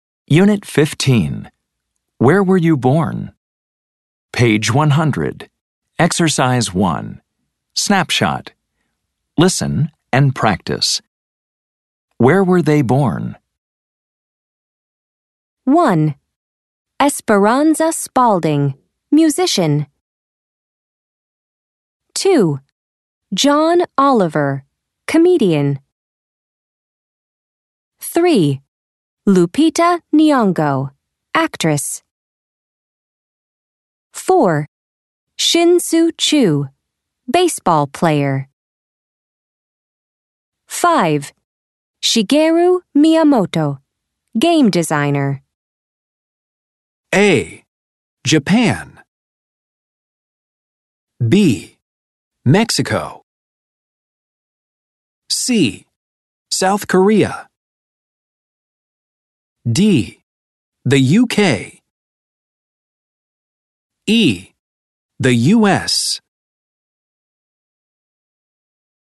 American English
This includes Snapshots, Conversations, Grammar Focus, Listening, Pronunciation practice, Word Power, and Reading, all recorded in natural conversational English.